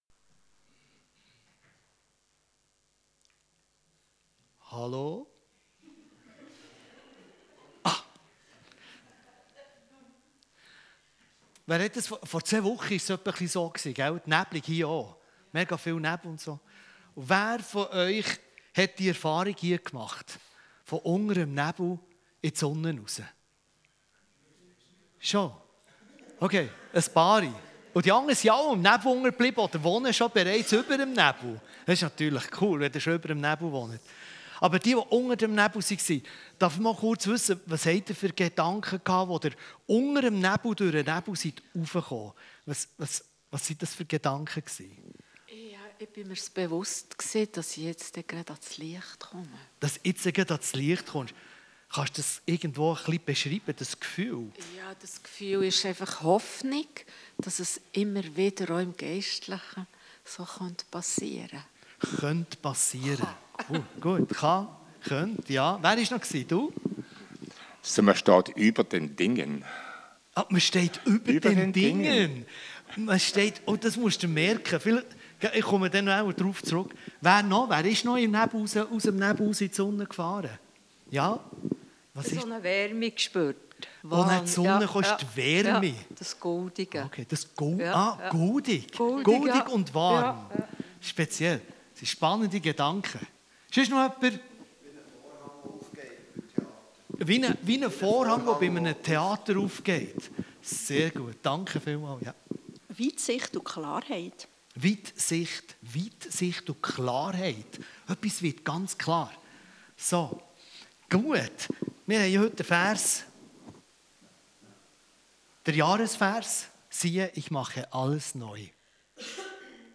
Sermons from Pfimi Bargen